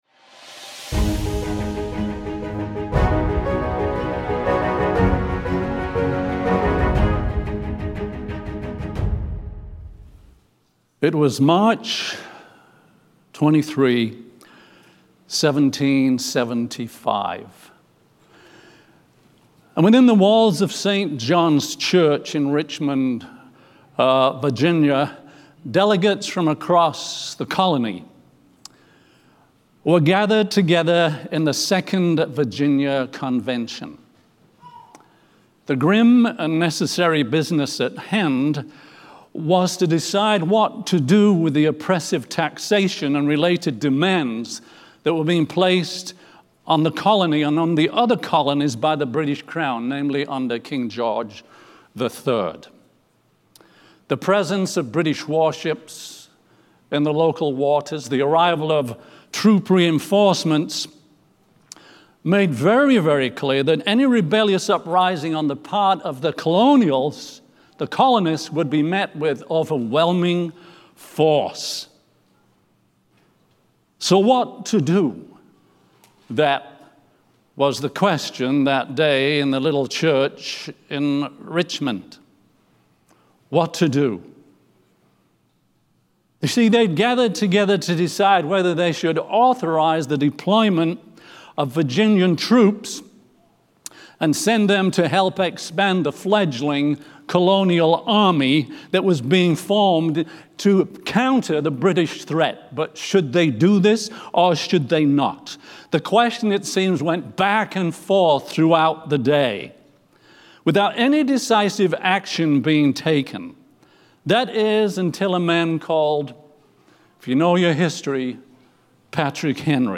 A message from the series "Central Sermons."
From Series: "Central Sermons"